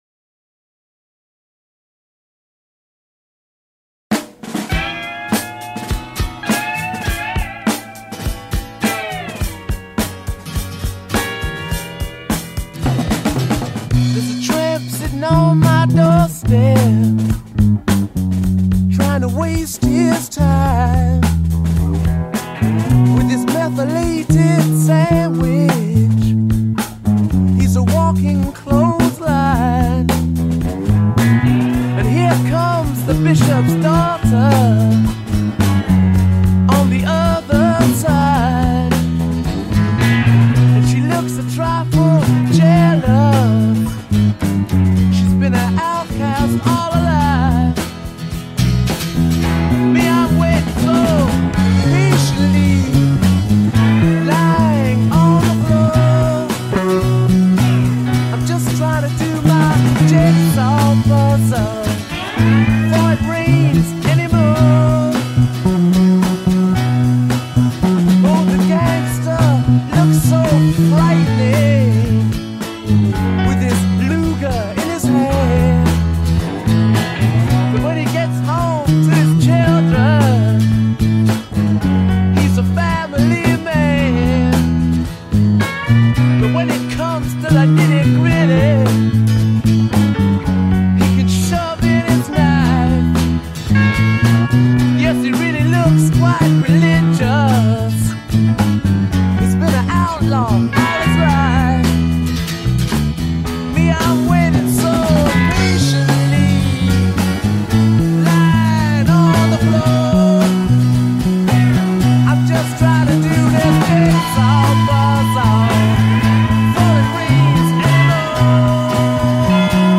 c’est une sorte de mélopée blues